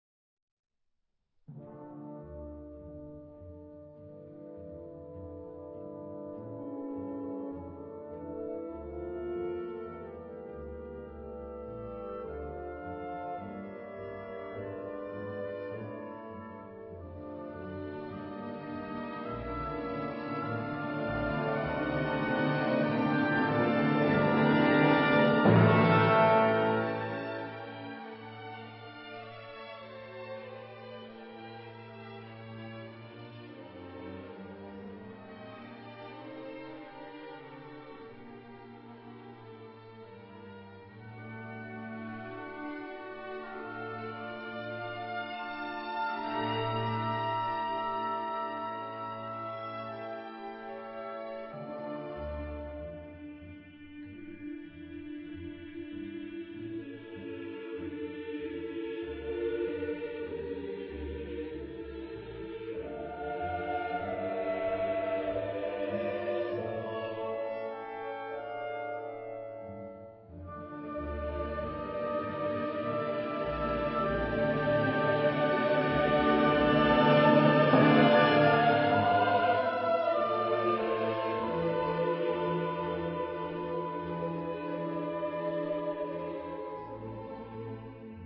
Genre-Style-Form: Sacred ; Romantic ; Section of the mass
Mood of the piece: pleading
Type of Choir: SATB  (4 mixed voices )
Instruments: Organ (1)
Tonality: D minor